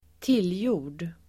Ladda ner uttalet
tillgjord adjektiv (om människor), artificial [used of people] Uttal: [²t'il:jo:r_d] Böjningar: tillgjort, tillgjorda Synonymer: konstlad, krystad, låtsad, onaturlig, uppstyltad Definition: inte naturlig, konstlad
tillgjord.mp3